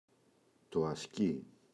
ασκί, το [a’ski]